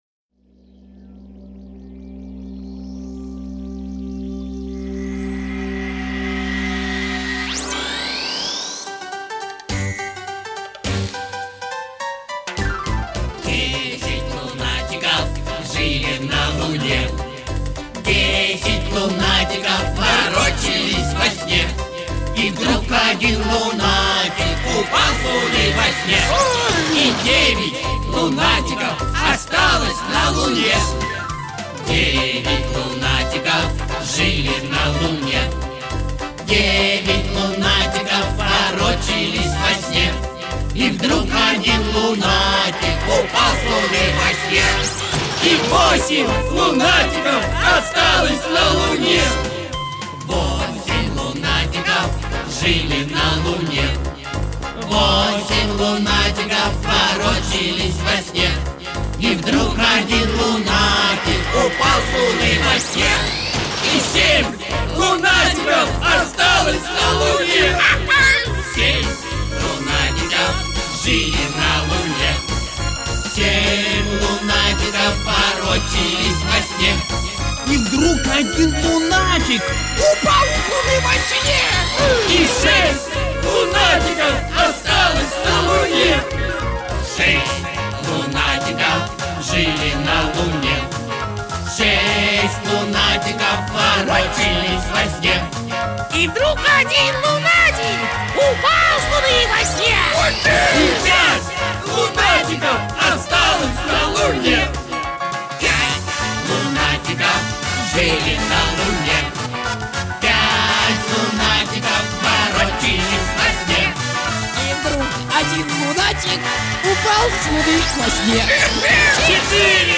• Качество: Хорошее
• Категория: Детские песни
шуточная